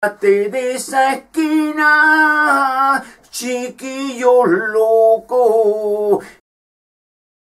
Cantando